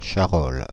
Charolles (French pronunciation: [ʃaʁɔl]
Fr-Paris--Charolles.ogg.mp3